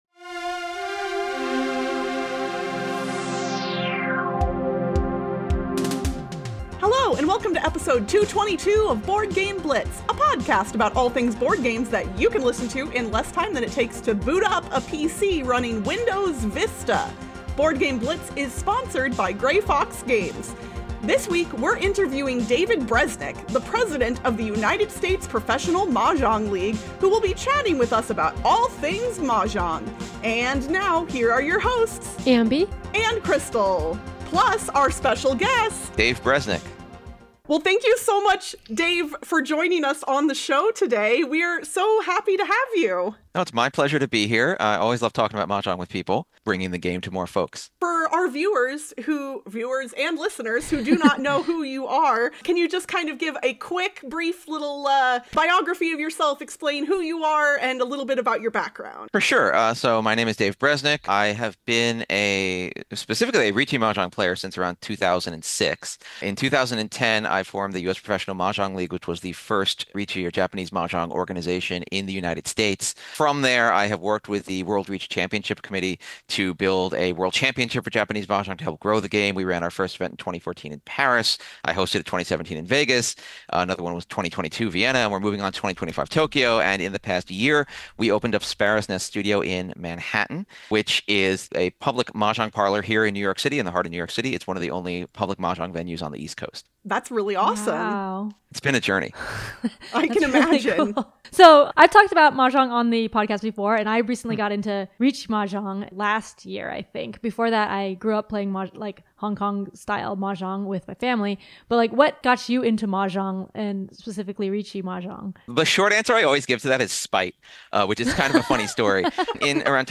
bgb-222-mahjong-interview.mp3